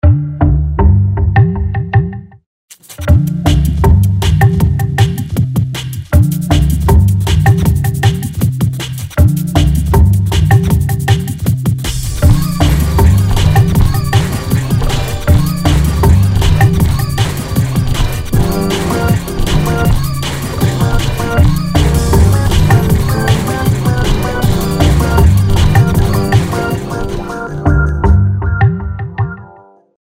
Dzwonek_2.mp3